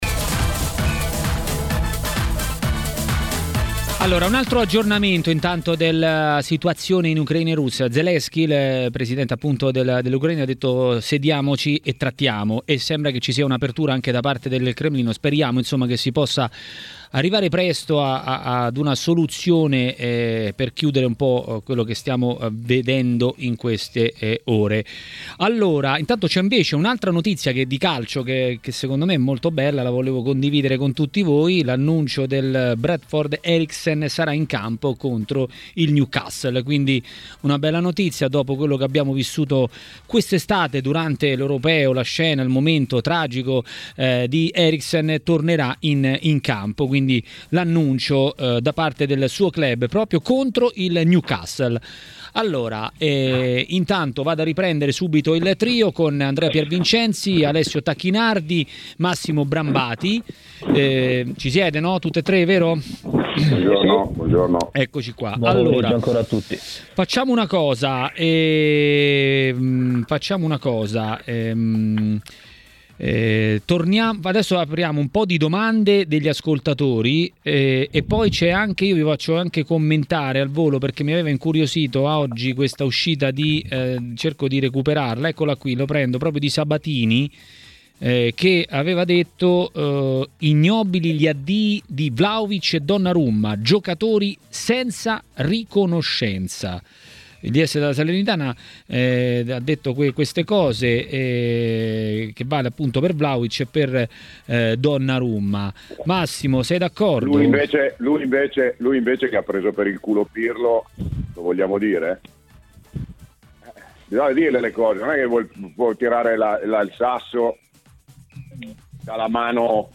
Maracanà, nel pomeriggio di TMW Radio